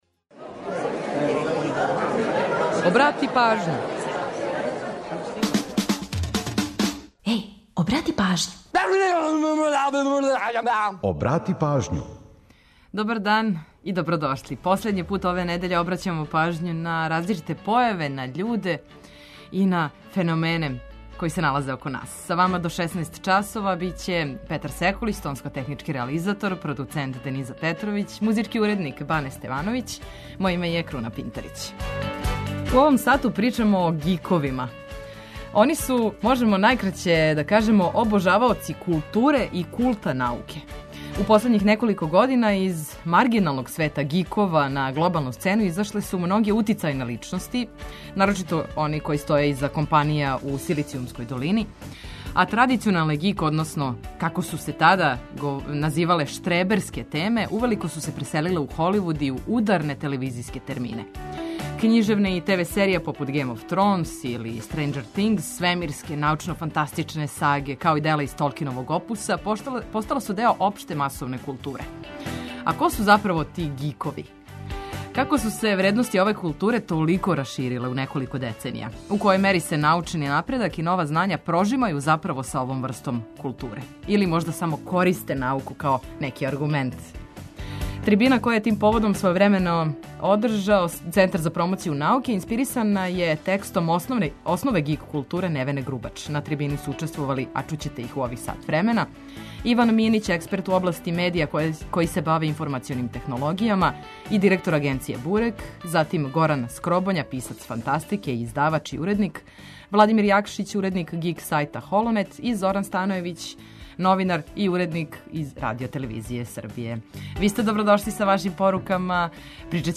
Трибина коју је тим поводом својевремено одржао Центар за промоцију науке инспирисана је текстом „Основе geek културе” Невене Грубач.